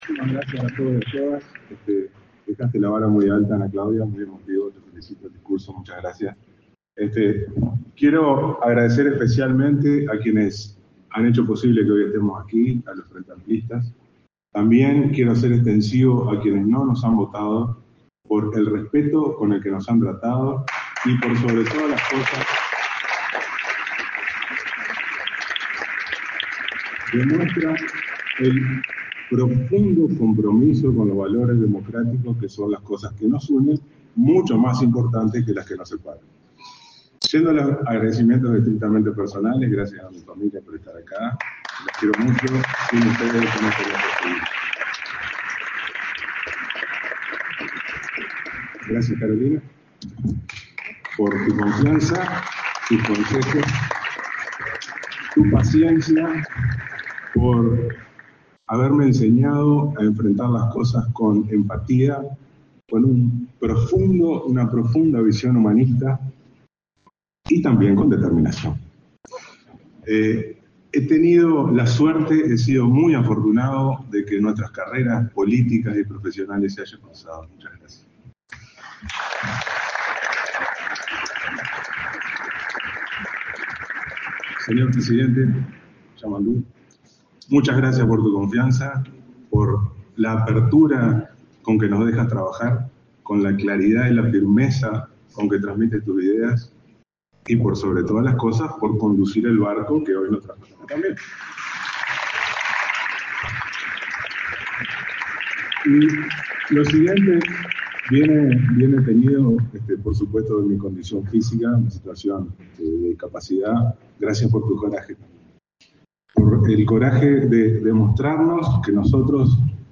Palabras del ministro de Turismo, Pablo Menoni
Palabras del ministro de Turismo, Pablo Menoni 06/03/2025 Compartir Facebook X Copiar enlace WhatsApp LinkedIn El presidente de la República, profesor Yamandú Orsi, junto a la vicepresidenta, Carolina Cosse, participó, este 6 de marzo, en la ceremonia de Pablo Menoni, como ministro de Turismo, y Ana Claudia Caram, como subsecretaria.